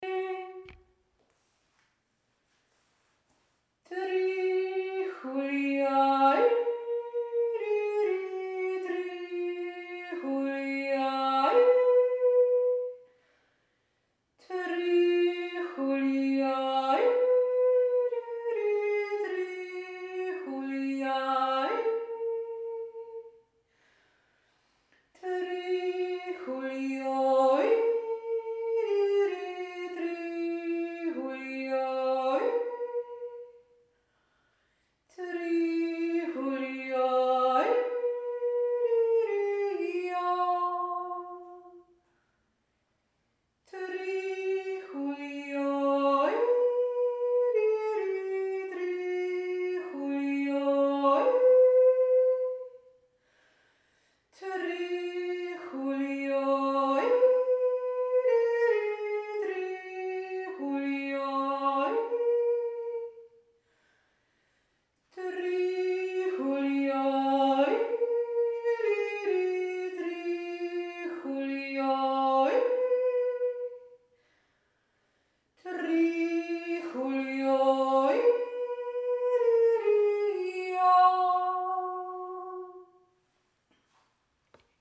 und so jodelten wir den Wurzhorner in der Jodelwerkstatt (5.2 MB)